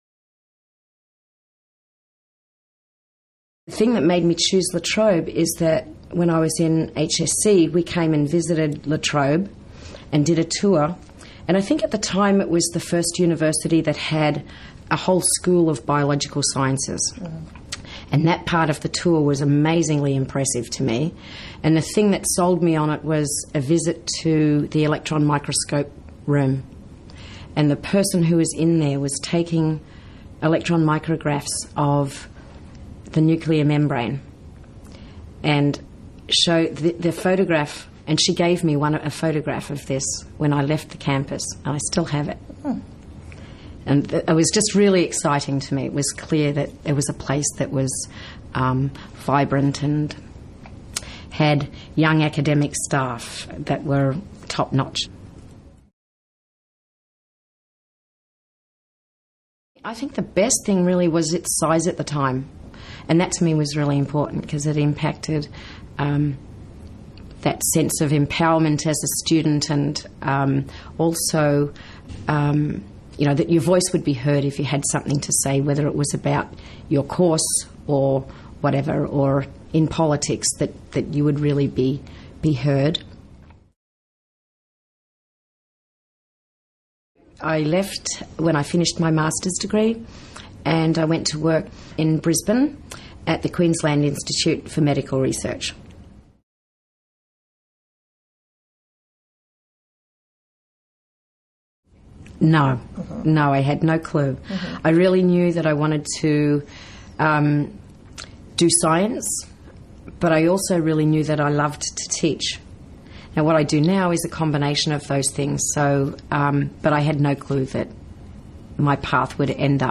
We were fortunate to have the opportunity to interview some of the Distinguished Alumni Awards winners about their time at La Trobe University.